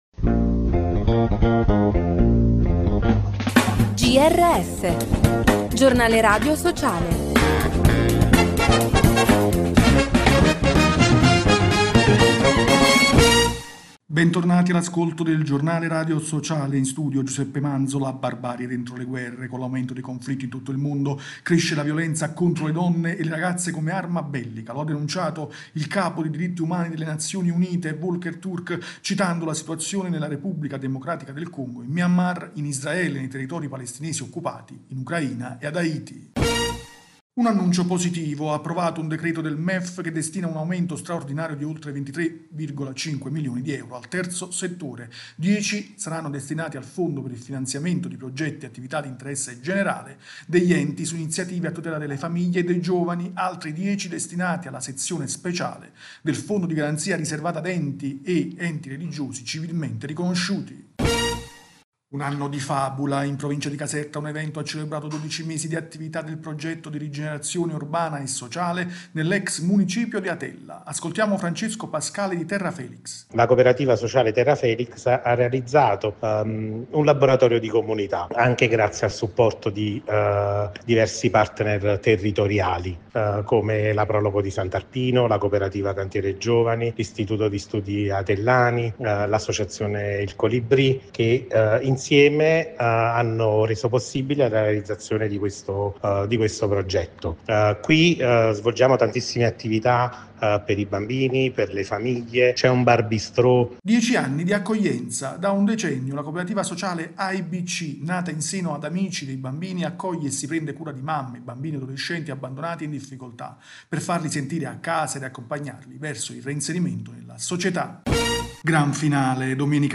Bentornati all’ascolto del Giornale radio sociale.